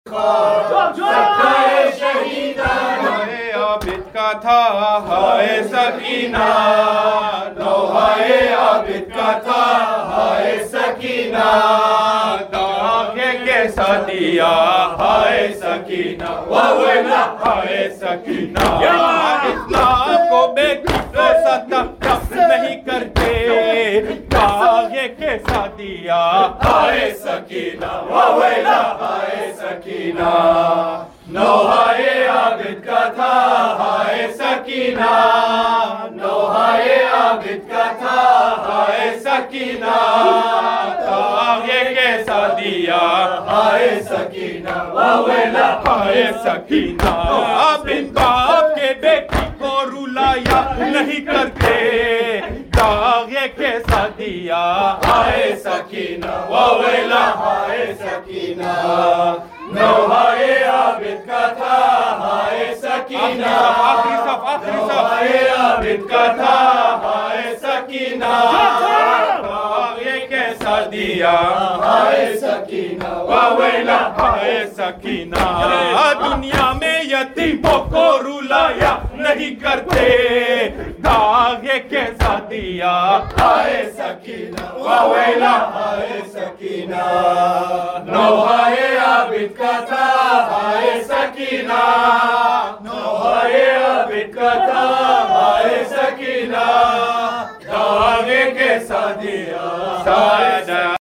Nawha Ye Abid Ka Tha Haye Sakina (Daagh Ye Kaisa Diya)